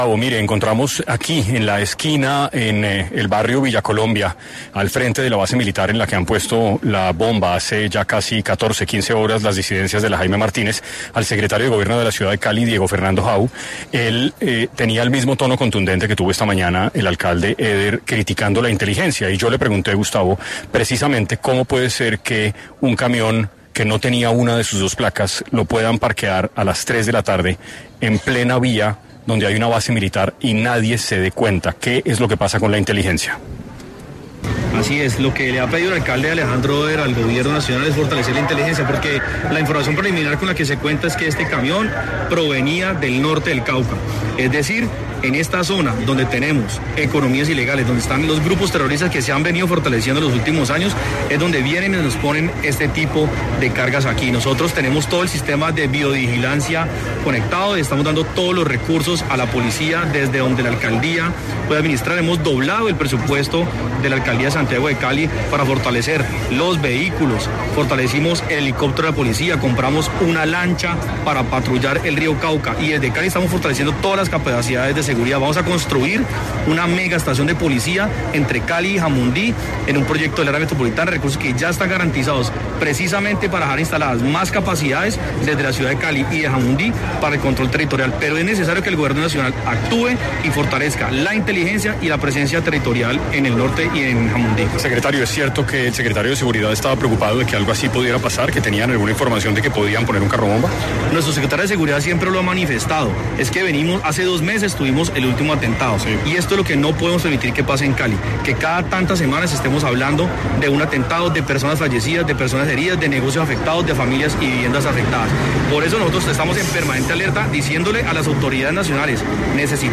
Diego Fernando Hau, secretario de Gobierno de Cali cuestionó en 6AM que uno de los capturados en el atentado de Cali tenía brackets.